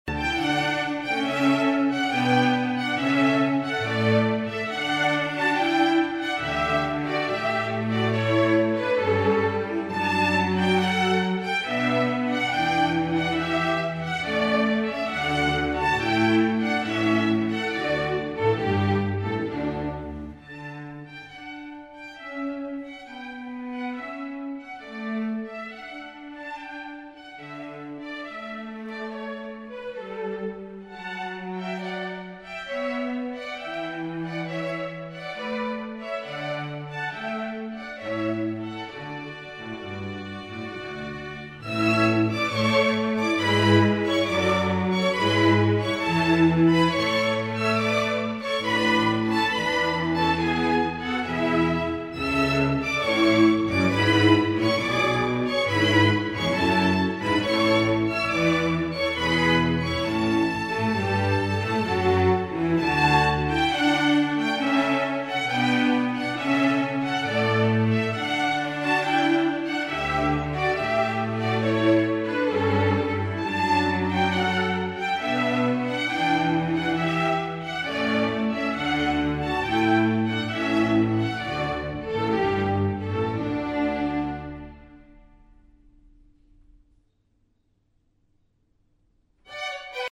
Répertoire pour Violon